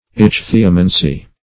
Search Result for " ichthyomancy" : The Collaborative International Dictionary of English v.0.48: Ichthyomancy \Ich"thy*o*man`cy\, n. [Gr.